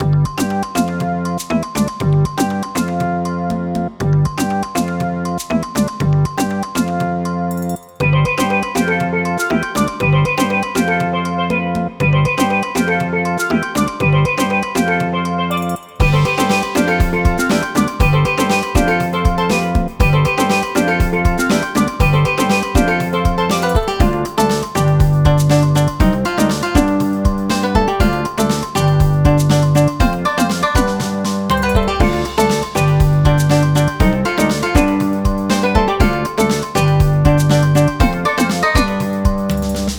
【イメージ】夏・ビーチ など